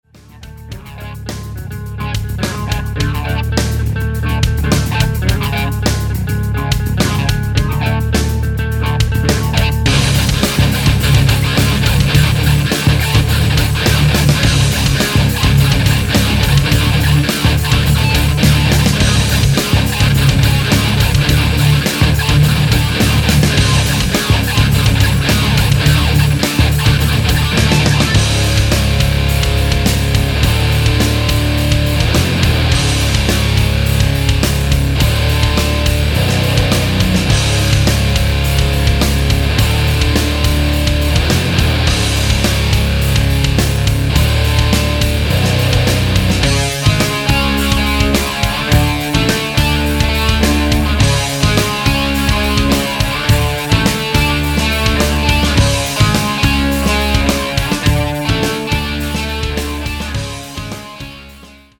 Saw Lead